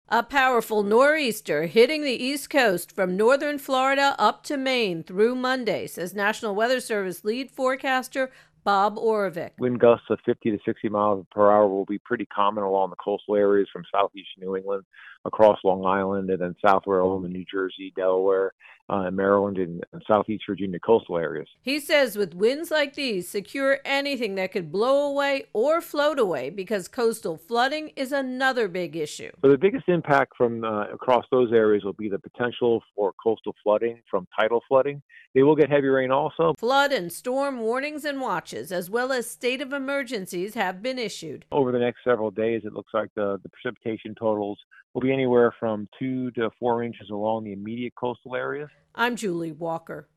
reports on the Nor'easter that will bring ocean flooding and high winds to the East Coast.